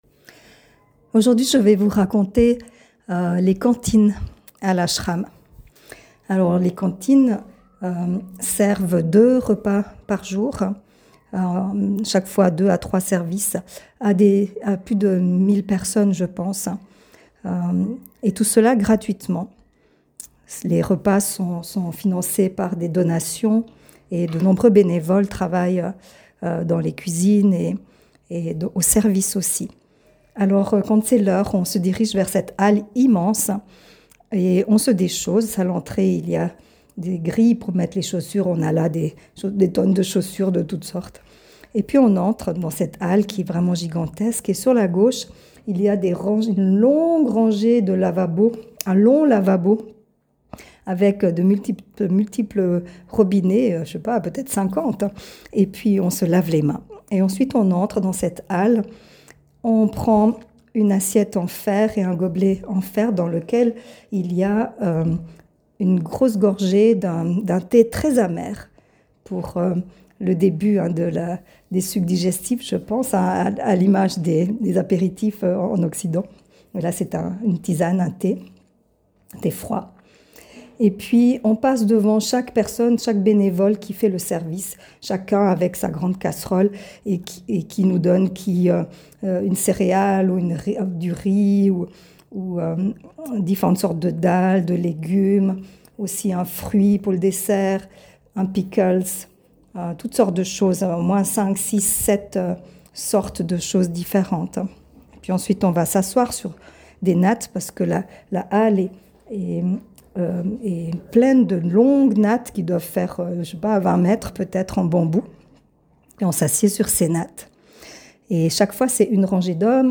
Ses notes vocales capturent l’instant et partagent ses impressions au fil du voyage…